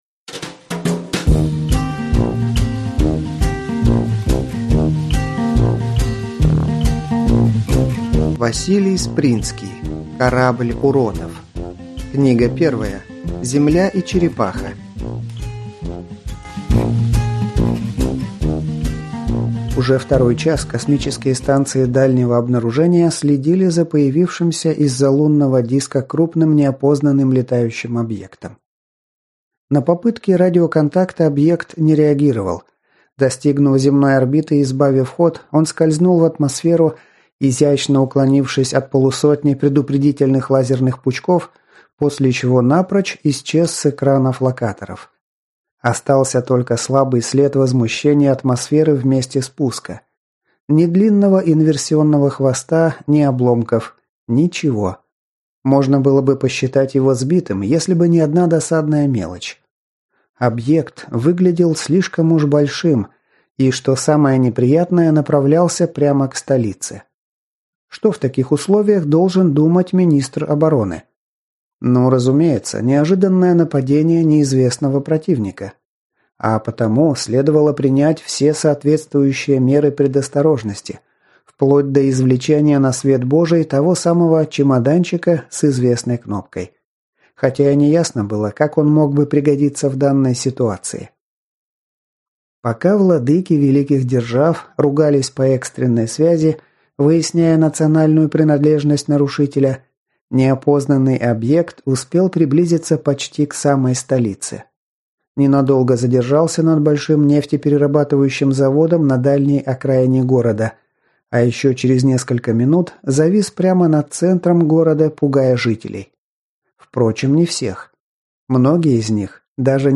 Аудиокнига Земля и Черепаха | Библиотека аудиокниг